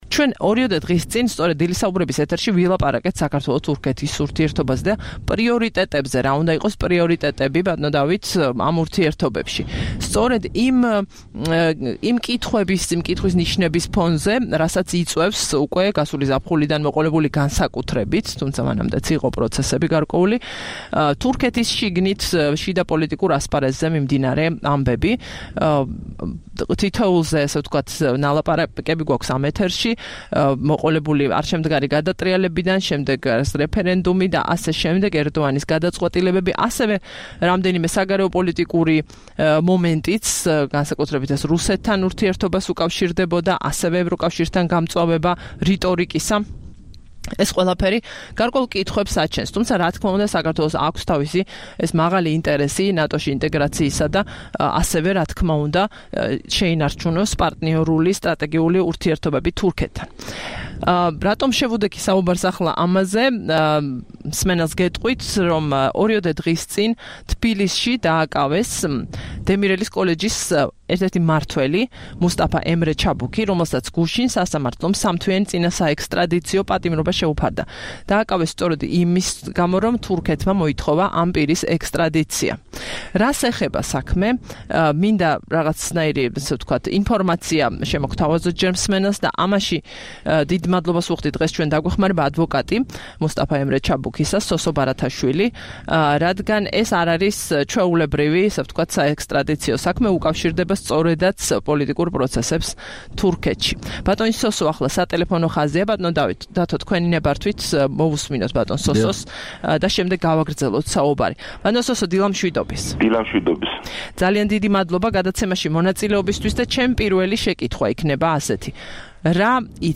ტელეფონით ჩაერთო